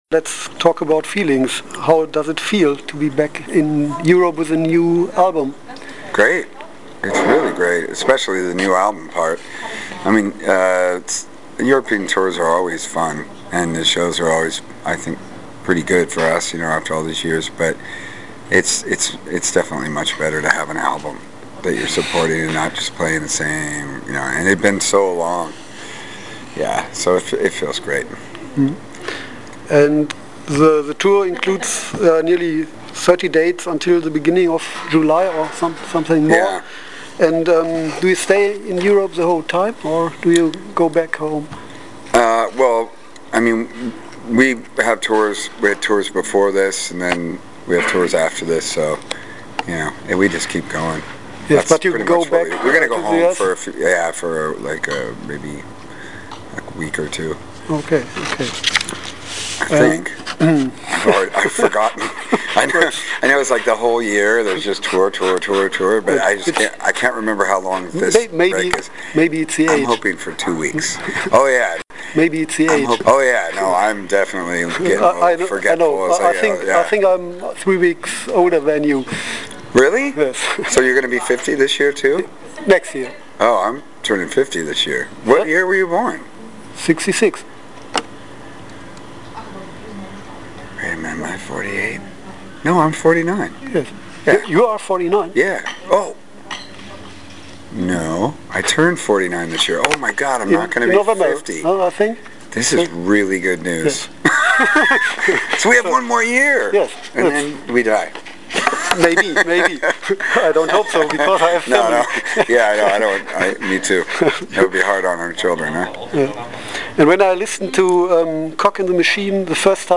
interview-mit-joey-cape-von-lagwagon-mmp.mp3